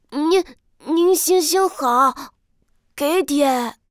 序章与第一章配音资产
c01_6卖艺小孩A_1.wav